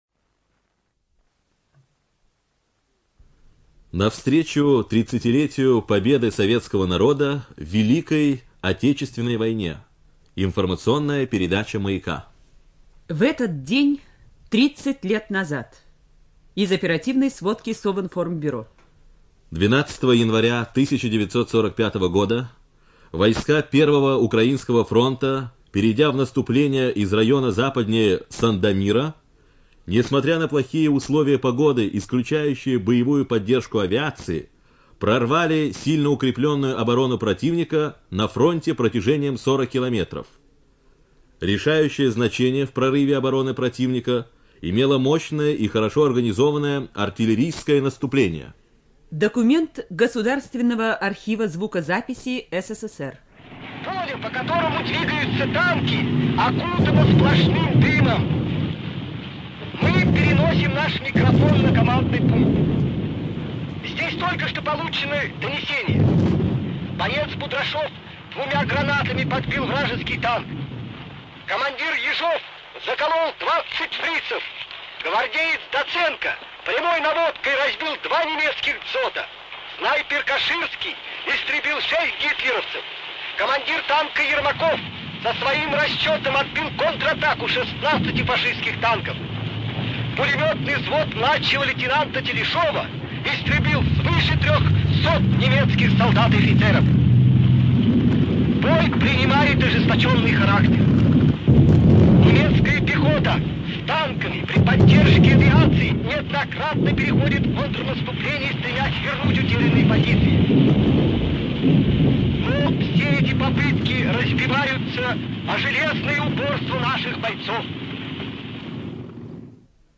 ЖанрДокументальные фонограммы